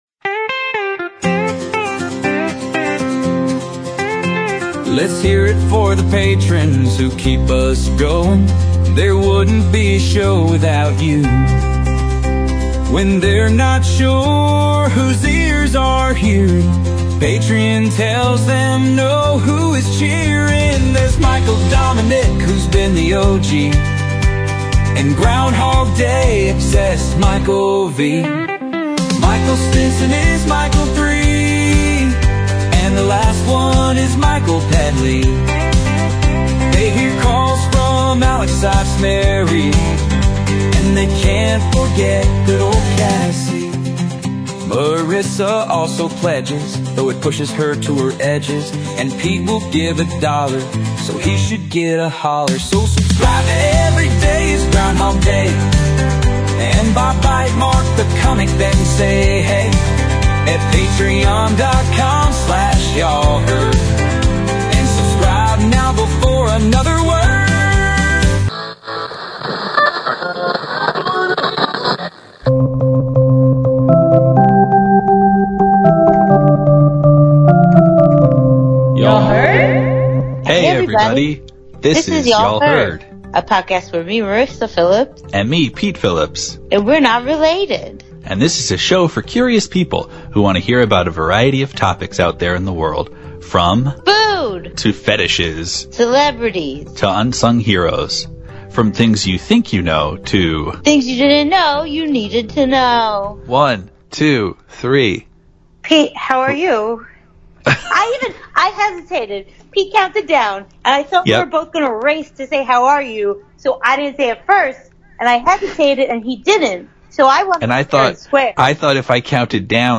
This week we begin with a little song for our Patrons.